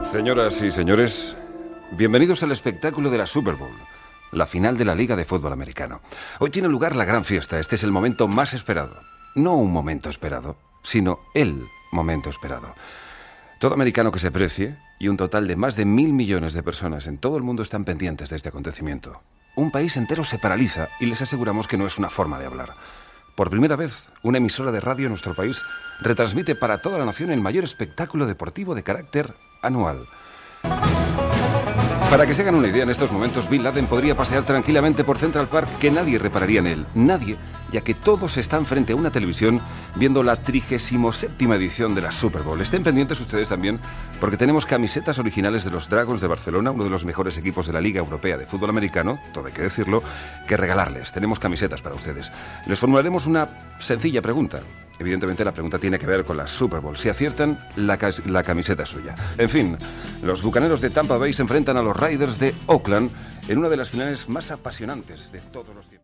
Presentació de la transmissió de la final de la XXXVII Super Bowl que es disputarà al Qualcomm Stadium de la ciutat de San Diego, California, entre els equips Oakland Raiders i els Tampa Bay Buccaneers.
Esportiu